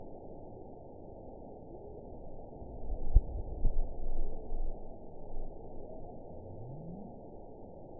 target species NRW